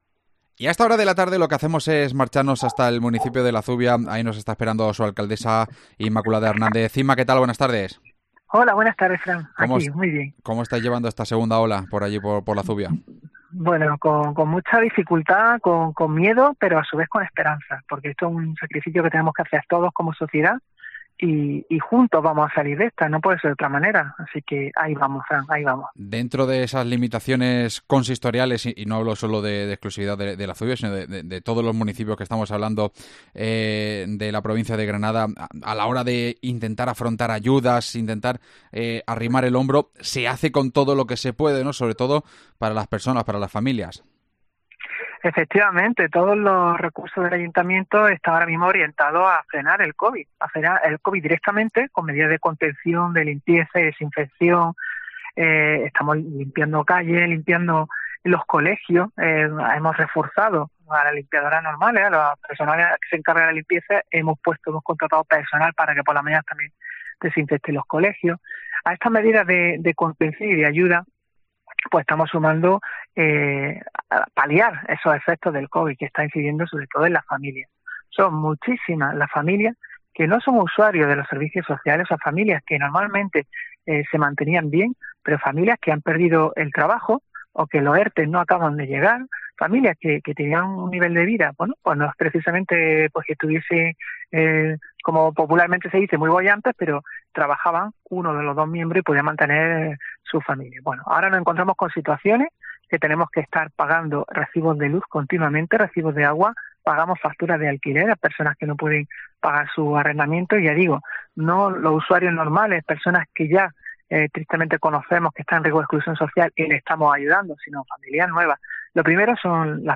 AUDIO: Nos atiende la alcaldesa del municipio Inmaculada Hernández